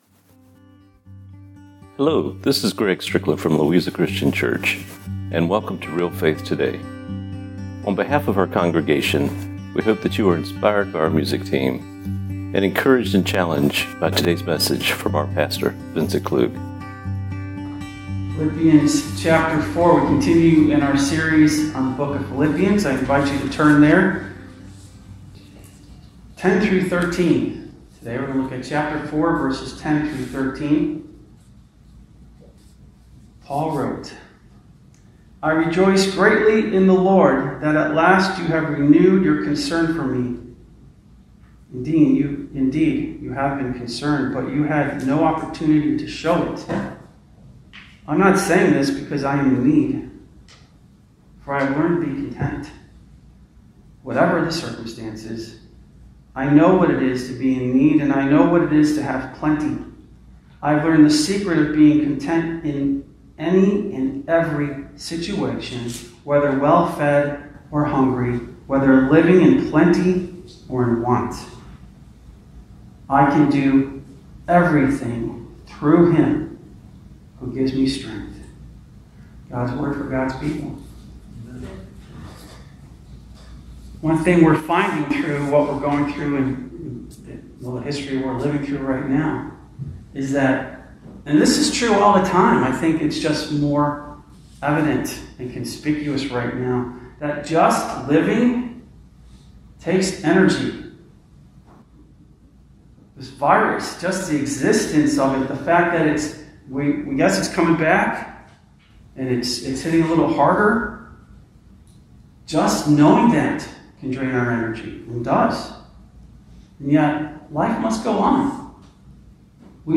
Radio Program